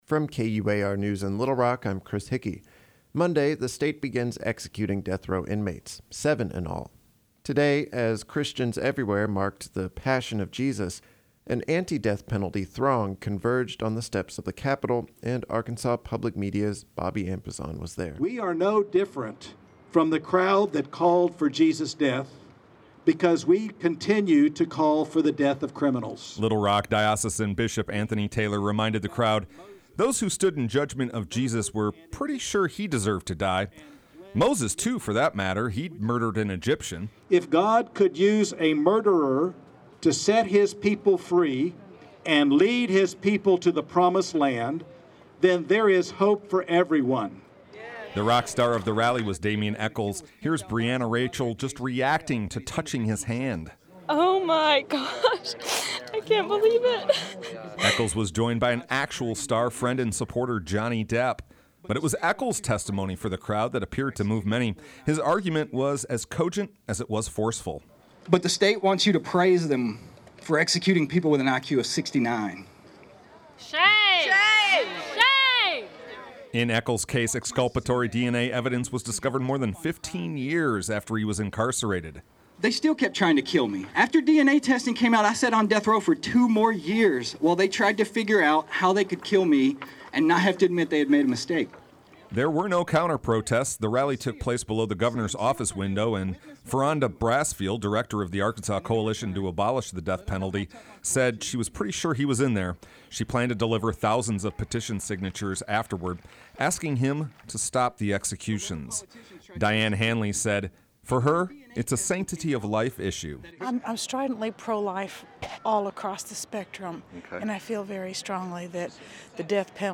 Sound stories
But today, as Christians everywhere marked the Passion of Jesus, an anti-death penalty throng converged on the steps of the Capitol.
The rally took place beneath the Governor’s office.
Rally.mp3